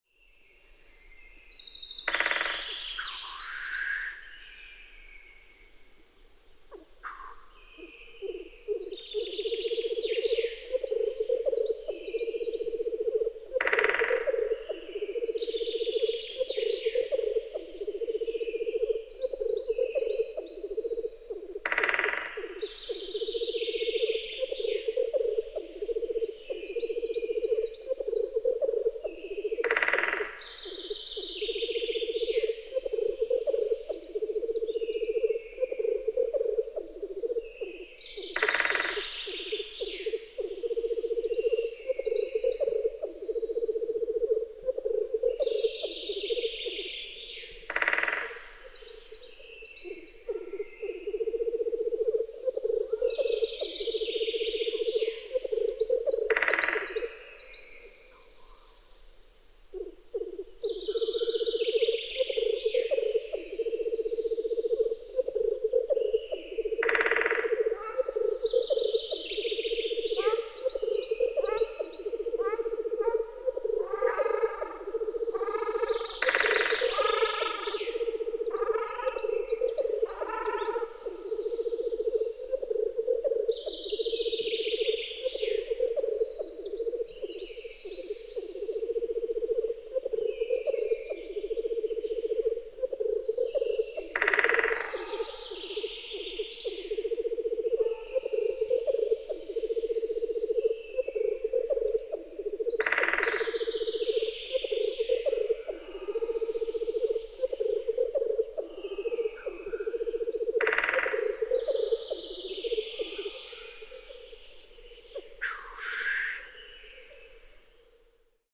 4 Black Grouse Tetrao terix, Great Spotted Woodpecker Dendrocopus major, Ruddy Shelduck Tadorna ferruginea,
Common Chaffinch Fringilla coelebs – WSRS Competition 2011